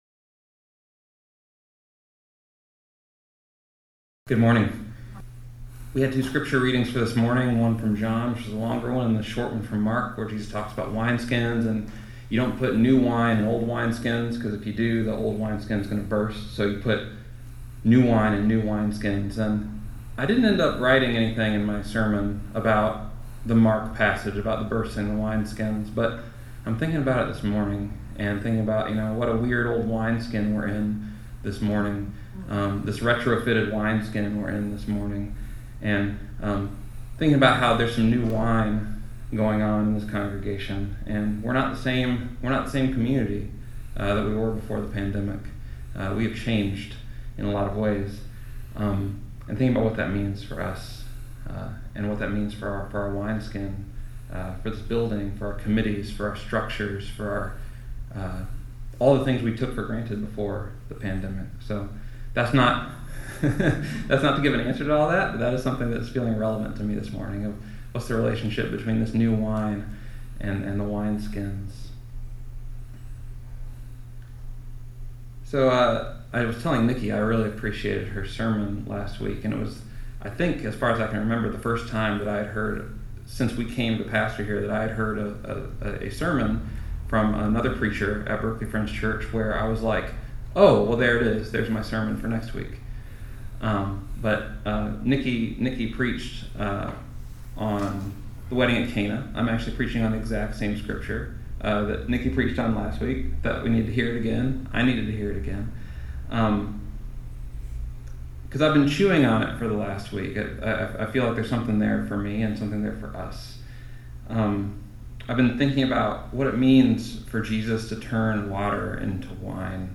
Listen to the most recent message from Sunday worship at Berkeley Friends Church, “Wine.”
(A technical glitch prevented the recording of the scripture passages.